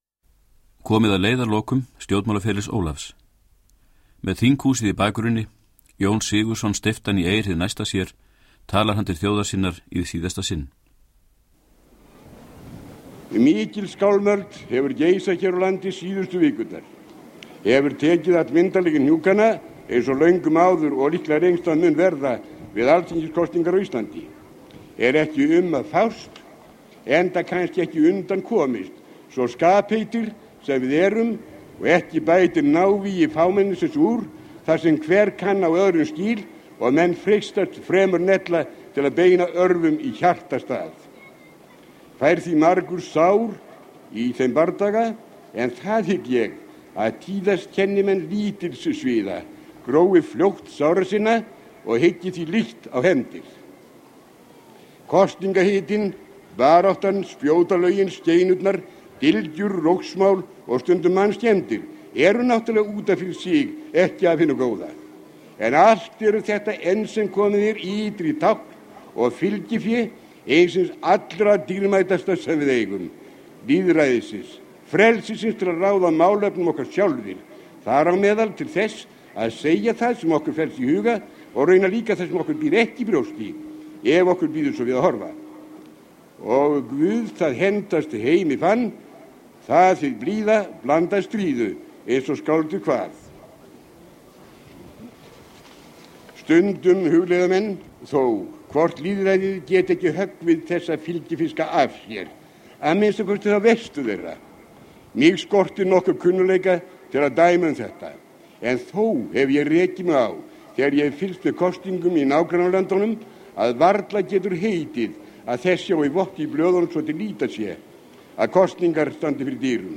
Upptökurnar af ræðum Ólafs Thors voru fengnar hjá safndeild Ríkisútvarpsins með góðfúslegu leyfi stofnunarinnar.
Ræða 17. júní 1963. Síðasta þjóðhátíðarræða Ólafs.